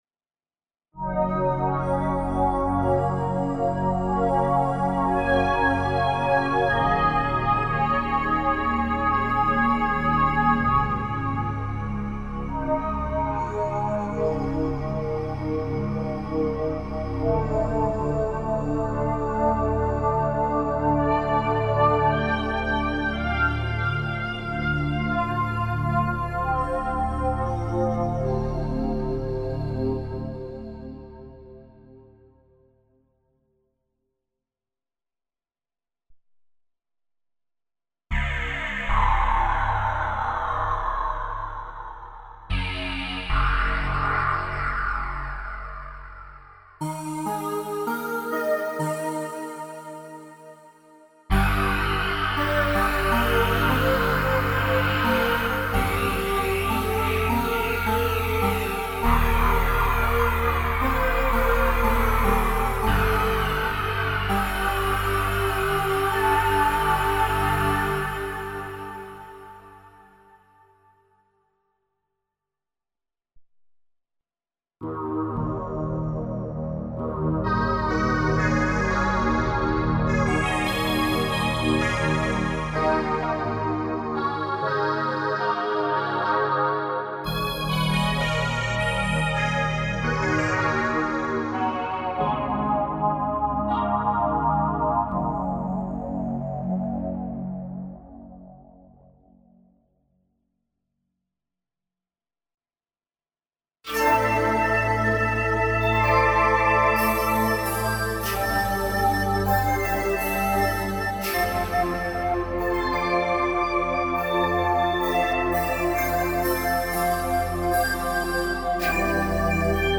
evolving-choir-pad-demo-radias.mp3